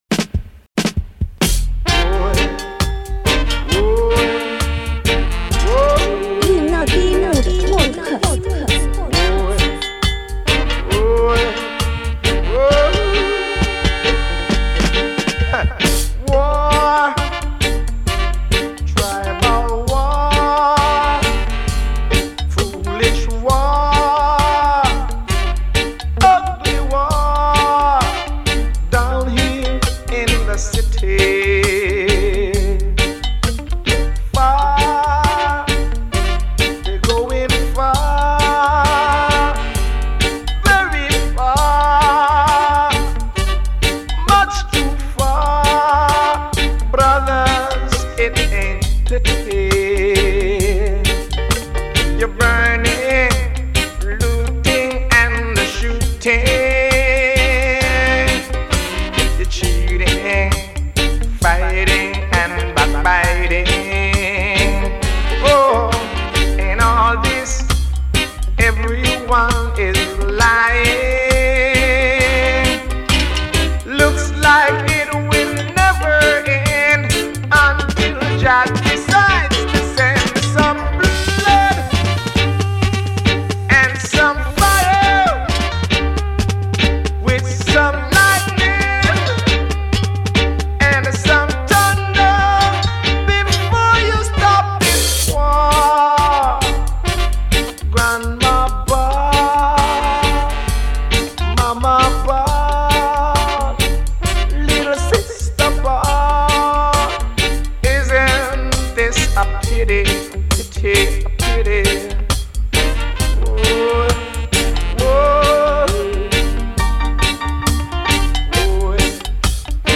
Some powerful and passionate Roots Reggae…